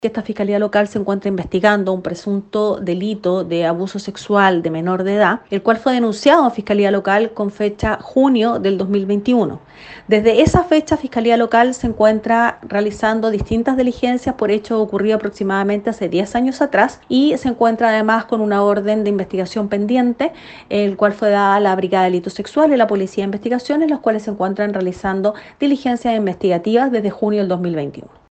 En detalle, la fiscal María Angélica de Miguel detalló que esta investigación judicial data desde el mes de junio del año 2021, fecha en la que se generó la denuncia contra un miembro de la iglesia osornina por su presunta vinculación con una menor de edad.